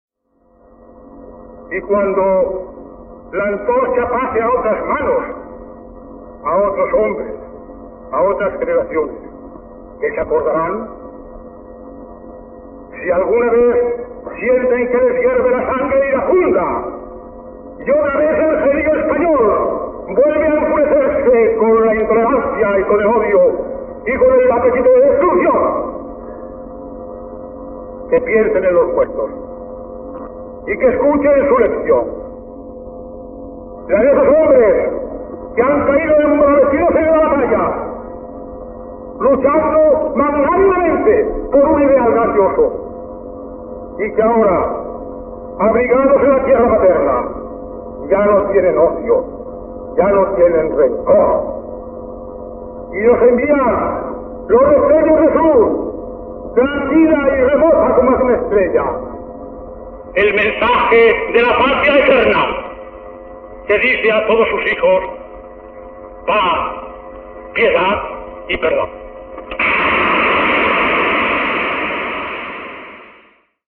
azana_discurso_barcelona.mp3